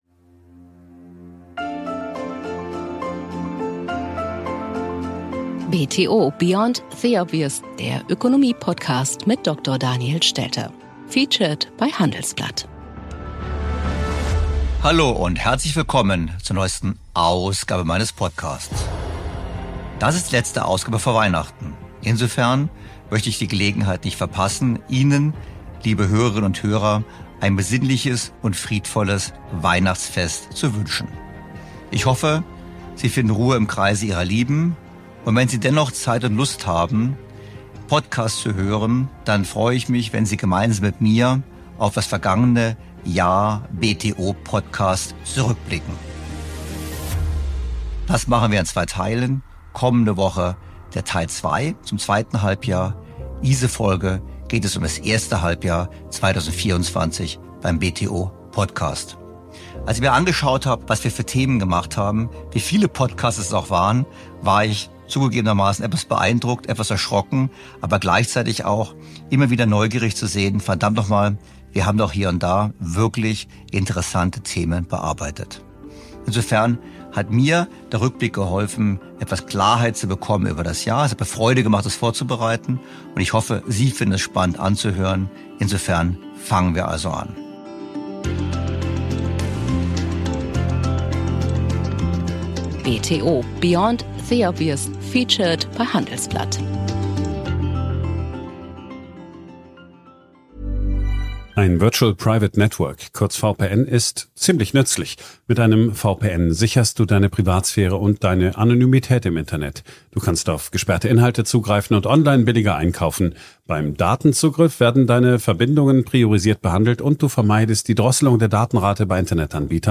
Traditionell ist das die Zeit für einen Blick zurück auf überaus spannende zwölf Podcast-Monate. Im ersten Teil des Jahresrückblicks haben wir die interessantesten Gespräche und Themen aus dem ersten Halbjahr 2024 ausgewählt.